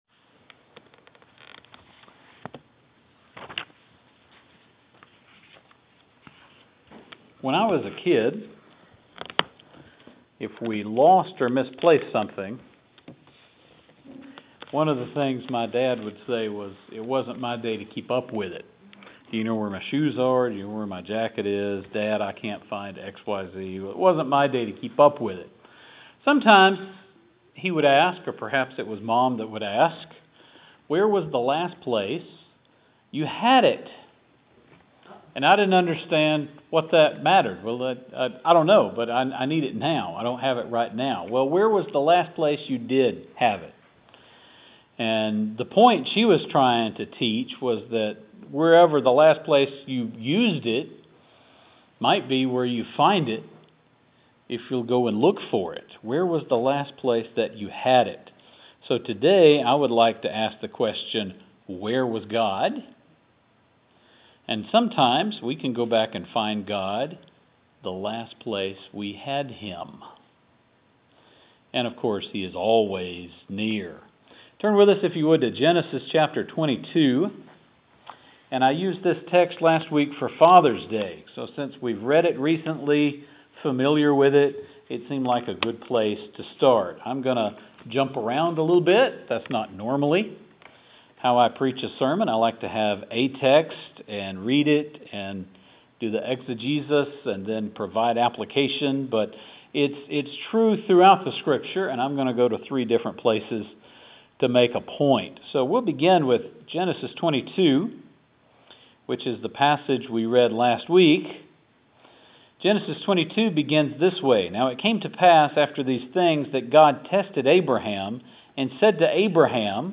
Today was our first day back in person since March 15th. Today's sermon picks up in Genesis 22, the same text we read last week on Father's Day.